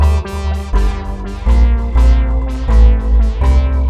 Orbital Poly D 123.wav